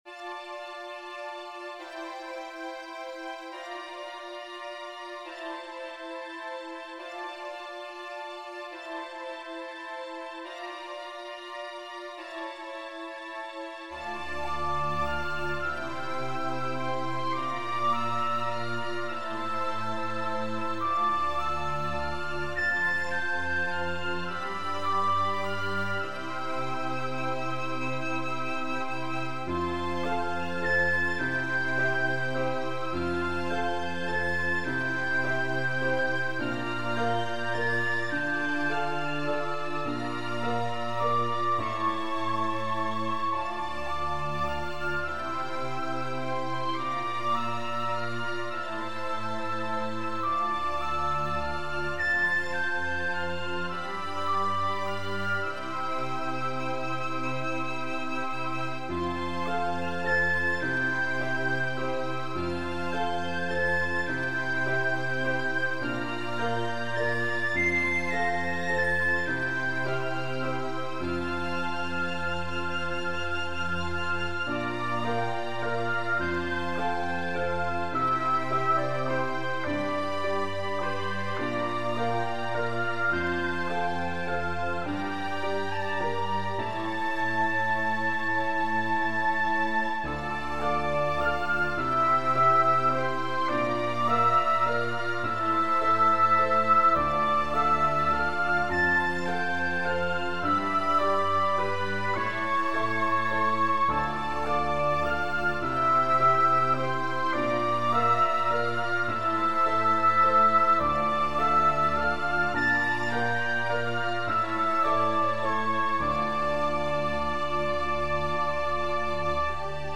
symphony in 159edo
... 0:00-1:59 The Journey Begins (Passacaglia) ...
... 5:49-6:44 A Meaningful Dance (Minuet & Trio) ... ... ... ... ... 6:44-10:31 Dissipation of Dreams (Fugue) ...
... 14:11-14:39 The Truth behind the Fantasy (Sonata-Allegro) ... 14:39-23:58 Notice that the spaces at 1:59-2:06 and 10:31-10:44 are simple transitions between movements, nothing fancy there...